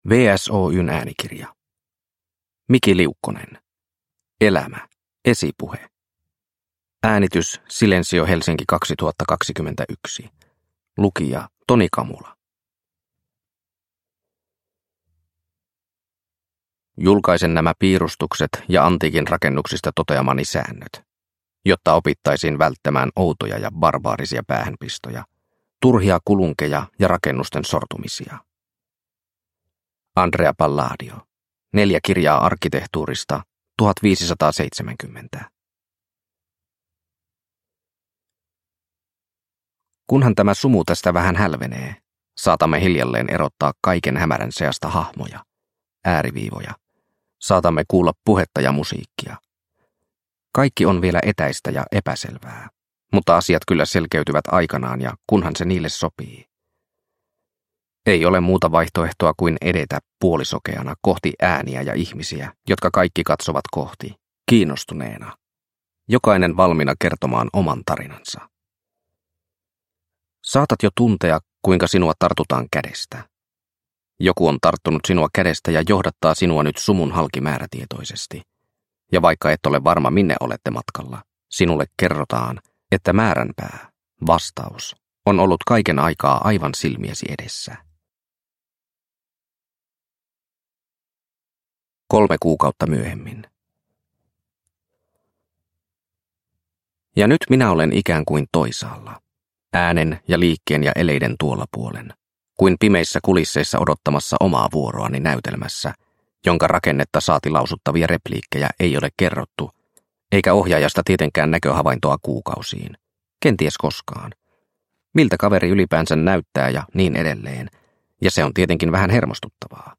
Elämä: Esipuhe – Ljudbok – Laddas ner